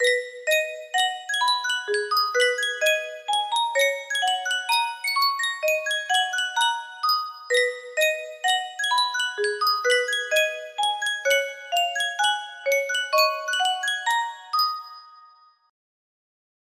Yunsheng Music Box - AAA LIFE 6388 music box melody
Full range 60